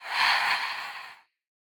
minecraft / sounds / mob / phantom / idle3.ogg